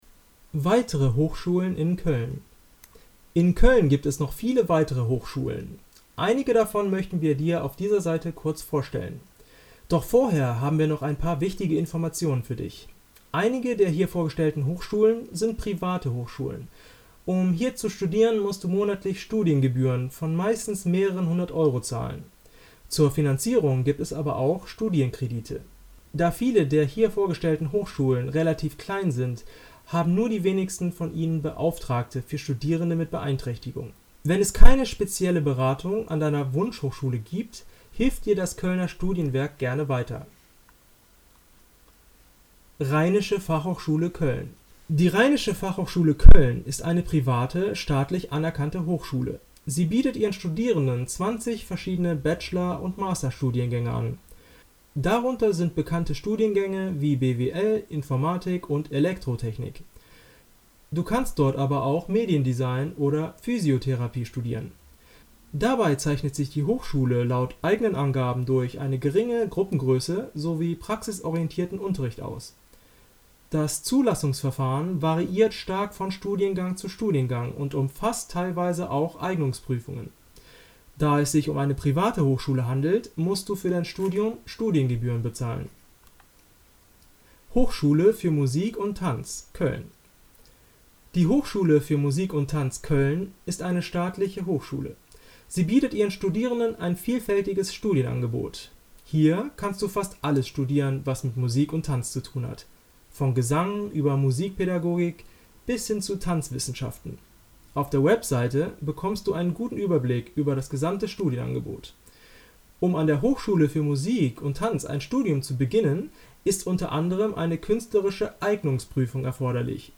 Wir haben ihn einfach für dich eingesprochen: